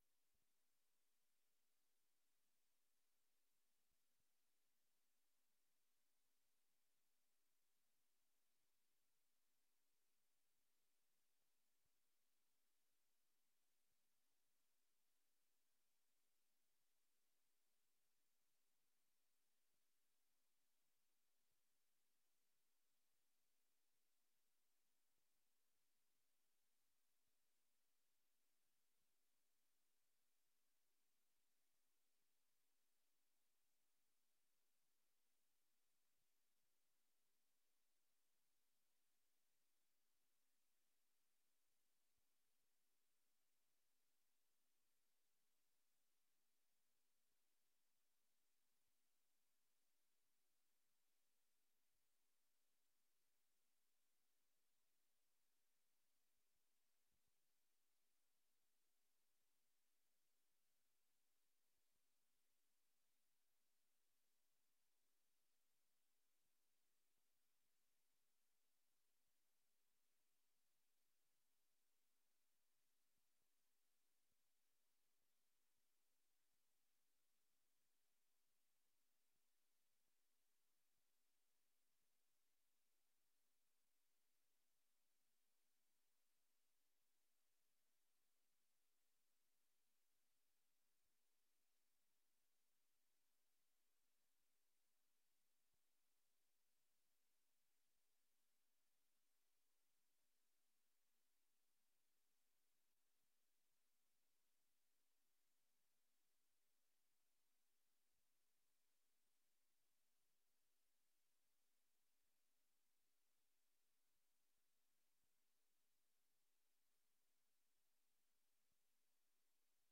Raadsvergadering 27 februari 2025 19:30:00, Gemeente Dronten
Download de volledige audio van deze vergadering